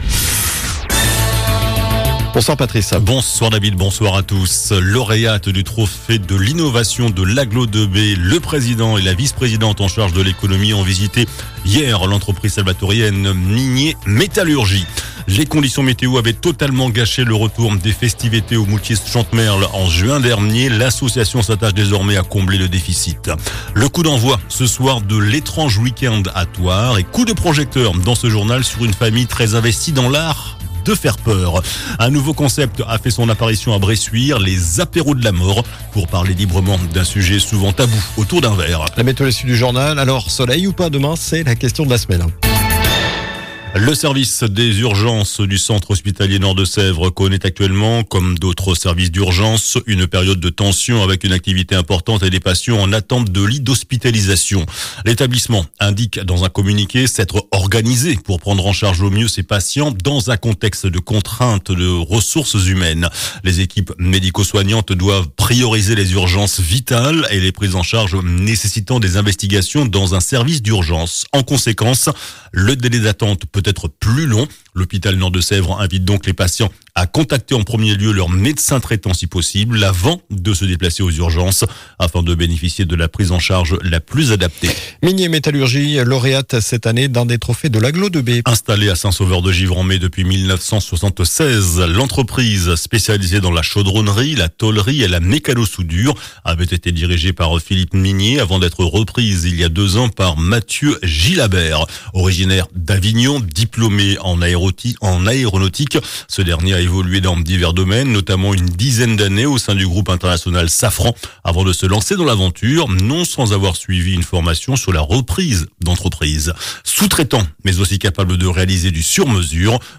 JOURNAL DU JEUDI 31 OCTOBRE ( SOIR )